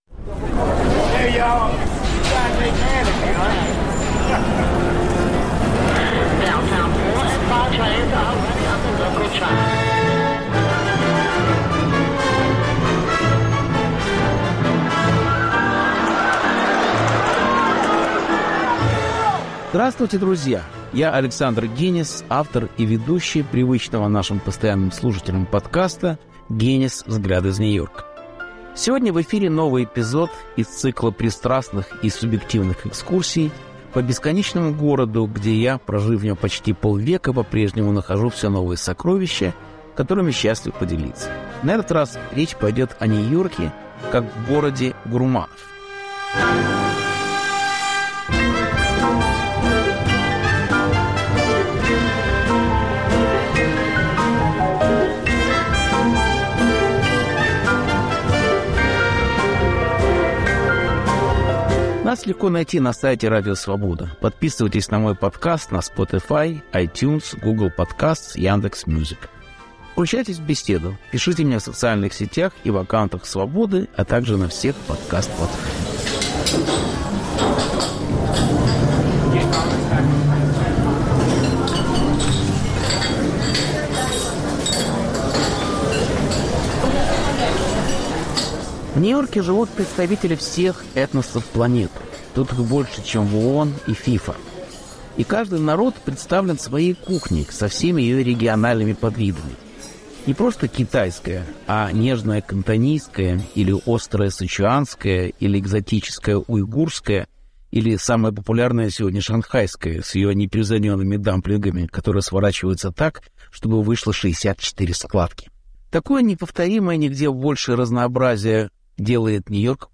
Повтор эфира от 09 апреля 2023 года.